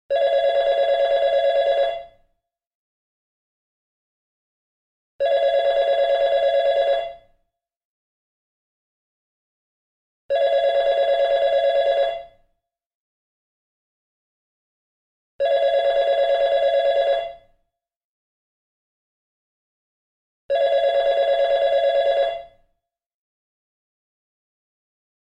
звонок офисного телефона